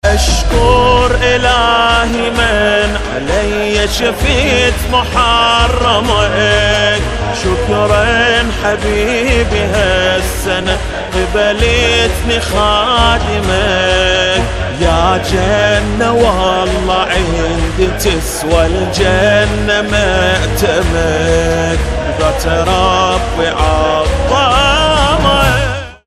زنگ موبایل (باکلام) محزون و حماسی
به مناسبت ایام اربعین حسینی(ع)